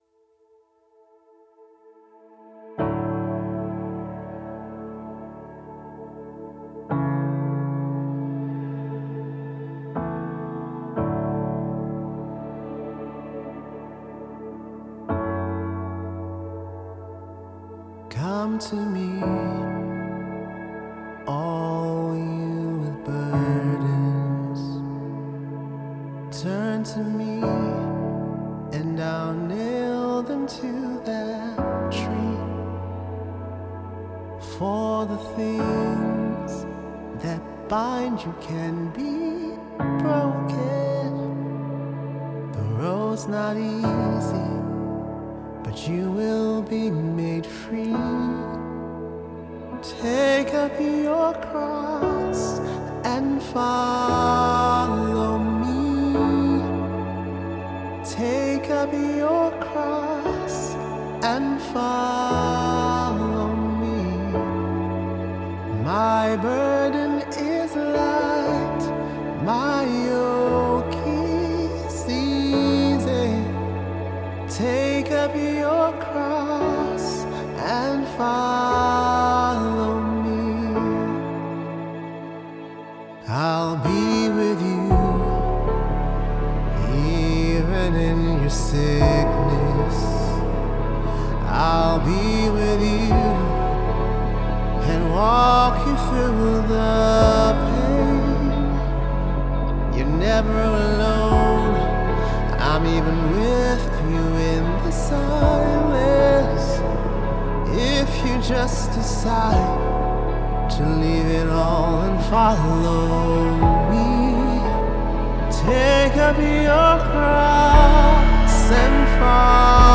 Gospel singer/songwriter and worshiper